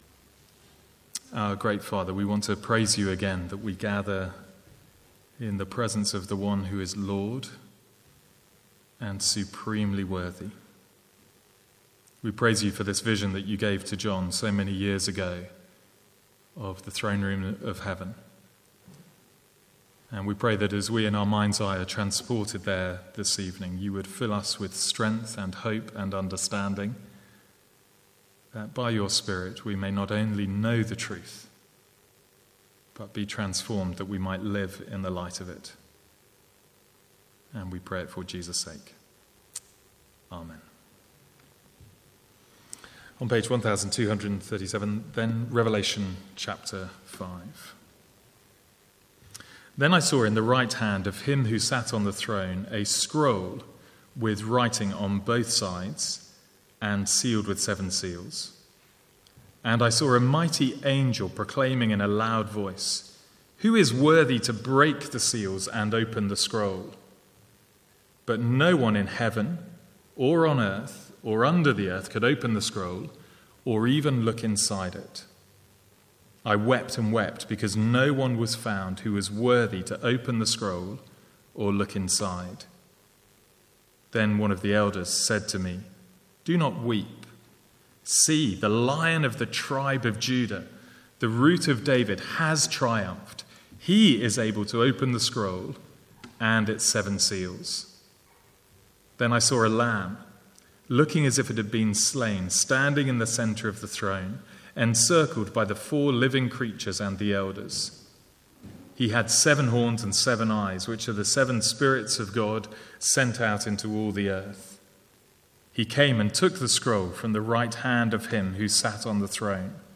Sermons | St Andrews Free Church
From the Sunday evening series in Revelation (recorded 13/7/14).